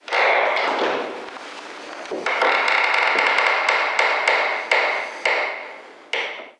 Muelles de una cama
ruido
Sonidos: Hogar